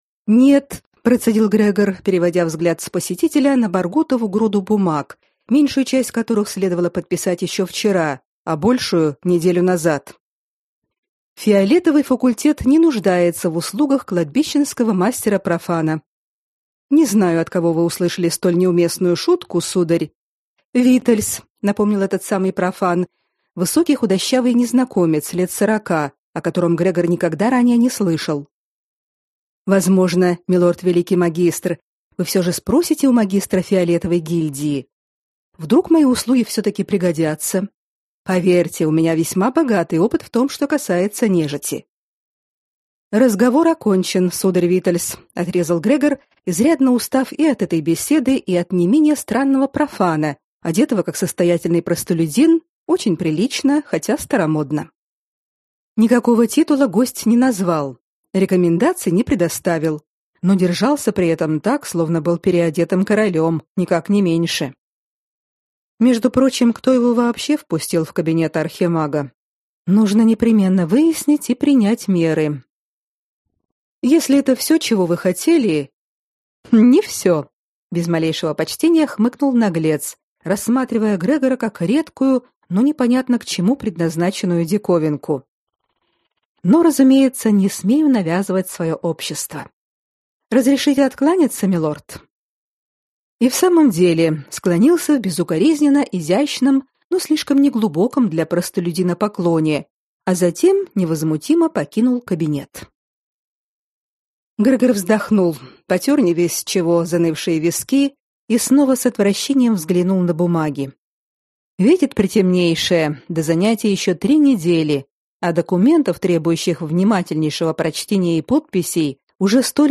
Аудиокнига Грани безумия. Том 1 | Библиотека аудиокниг